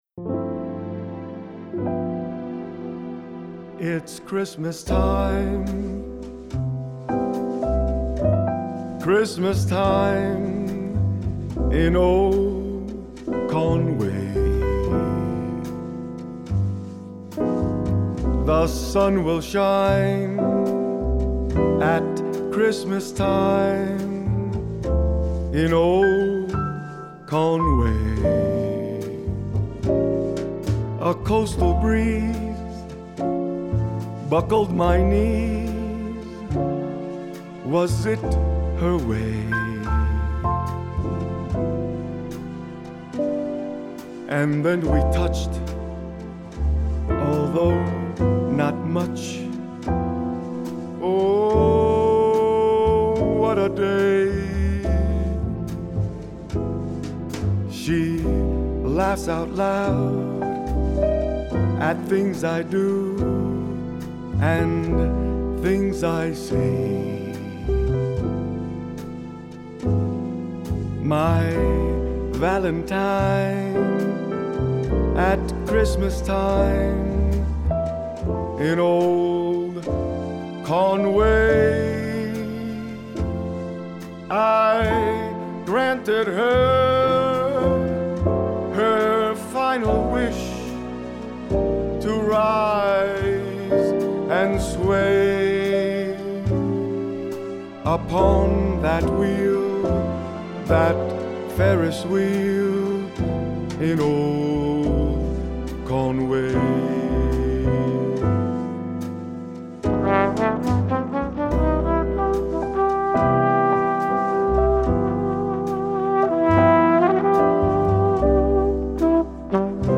Listen to the jazzy piece here –